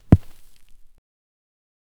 Sound effect of a small object falling onto dirt or soft soil. Light thud with soft dirt crunching, short and subtle. No background noise, no voices, no music 0:02 Huesos 0:10
sound-effect-of-a-small-oyhhi447.wav